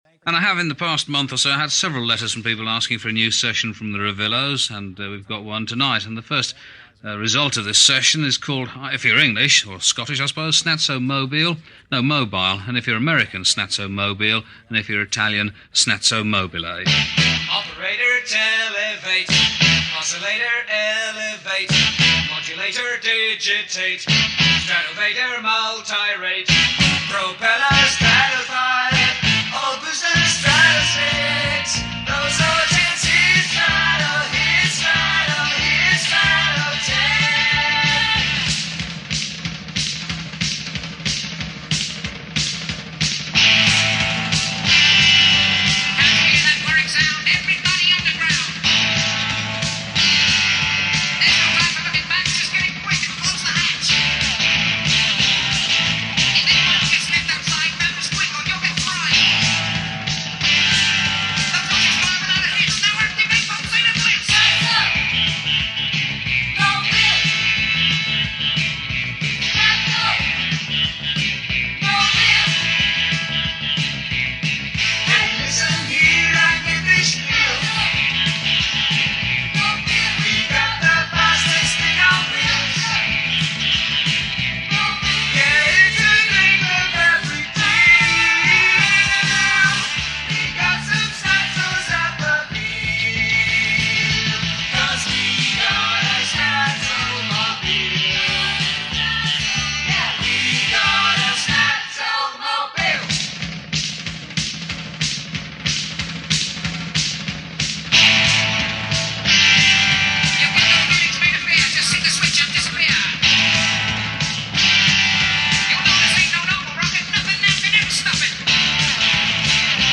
Punk/New Wave